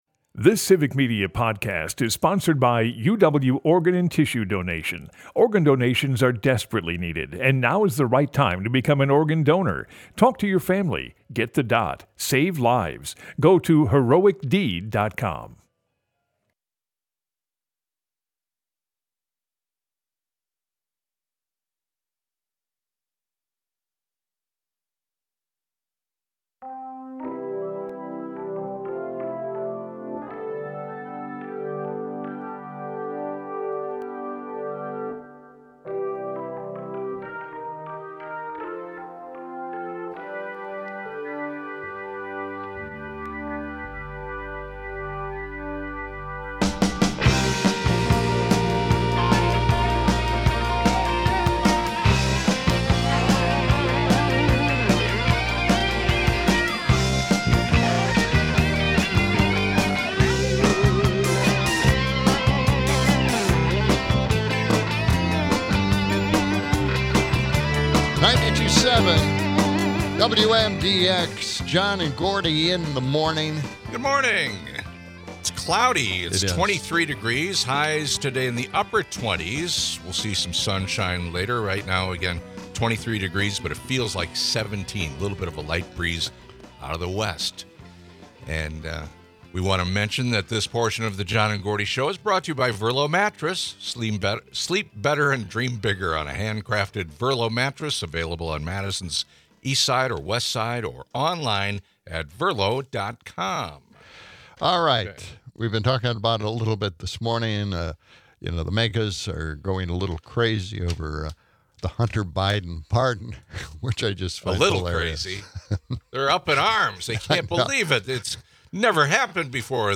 Broadcasts live 6 - 8am weekdays in Madison.